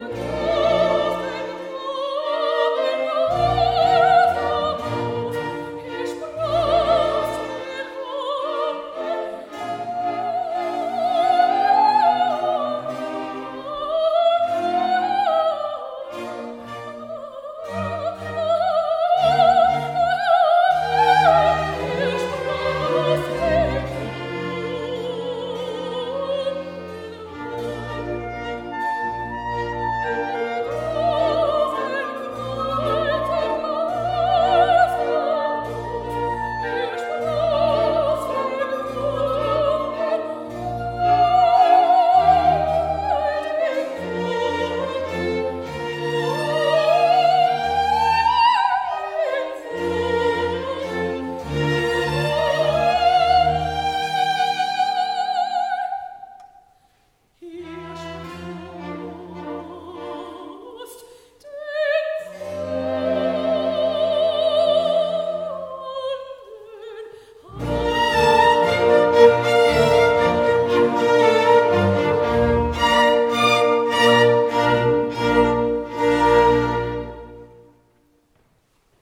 Oratorium